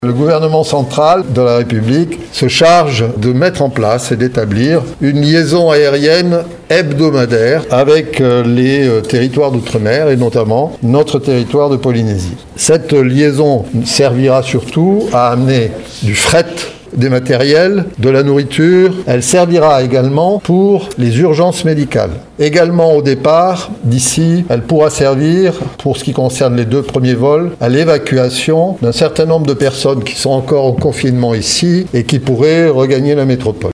Lors du point presse quotidien de la cellule de crise sanitaire du Pays, le ministre de la santé Jacques Raynal est revenu en détail sur les liaisons aériennes qui doivent permettre au pays de mieux s’équiper face à l’épidémie de coronavirus.